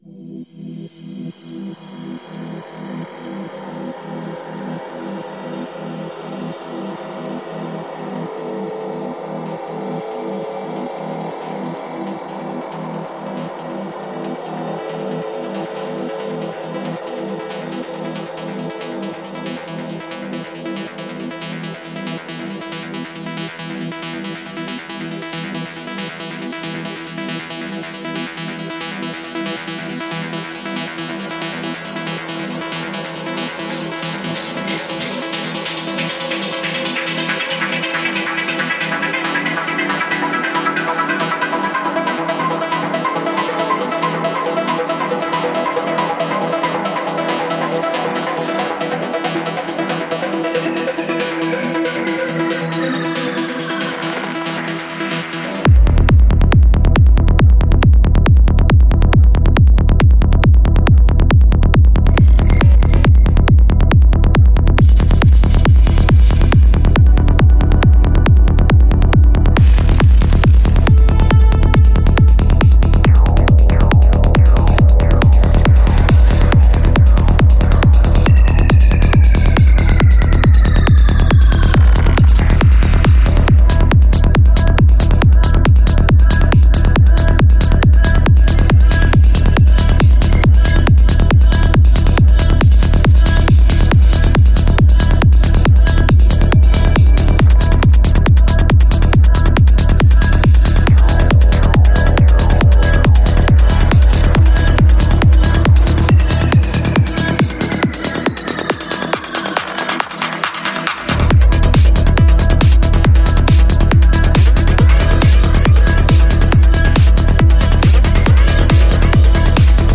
Жанр: Psy Trance